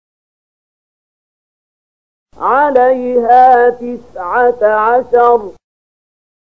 074030 Surat Al-Muddatstsir ayat 30 bacaan murattal ayat oleh Syaikh Mahmud Khalilil Hushariy: